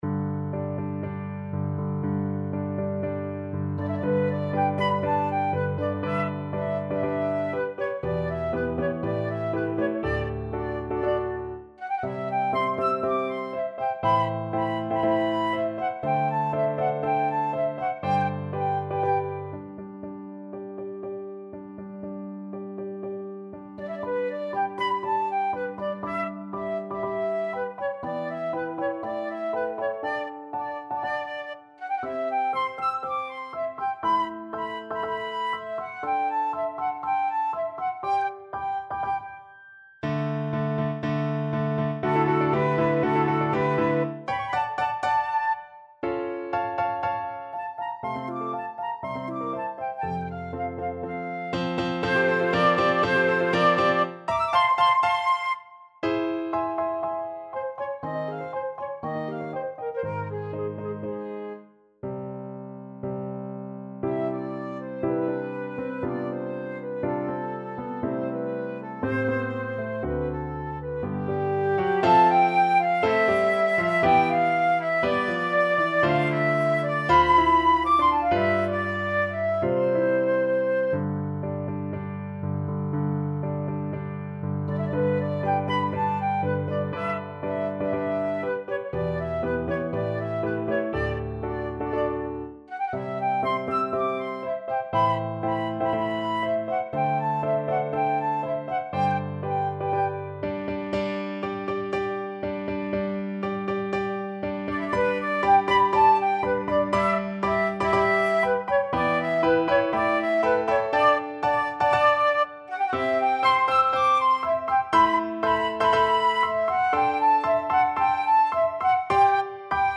Flute
G major (Sounding Pitch) (View more G major Music for Flute )
~ = 120 Tempo di Marcia un poco vivace
Classical (View more Classical Flute Music)